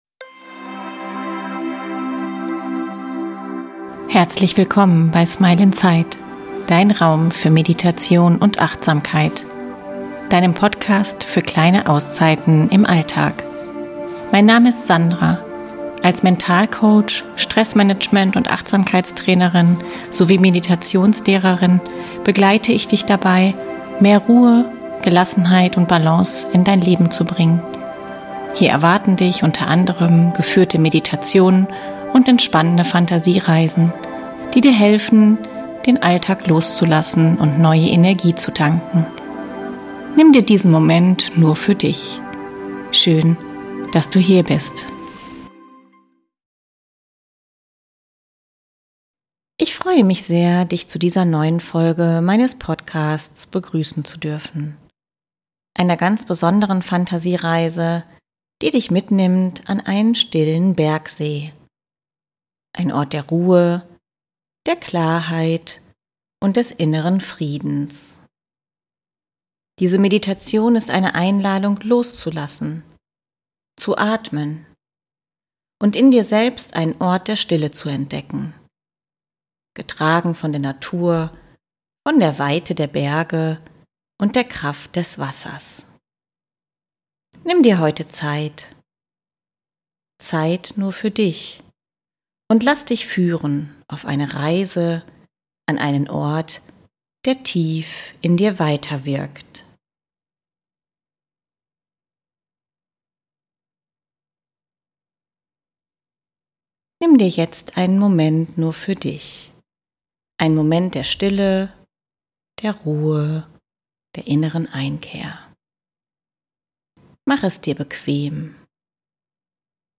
In dieser geführten Meditation begleite ich dich an einen friedlichen, abgeschiedenen Ort: den See der Stille. Du wanderst achtsam durch einen sanften Wald, folgst dem Lauf eines plätschernden Baches und erreichst einen stillen See, dessen beruhigende Atmosphäre dich sanft umhüllt.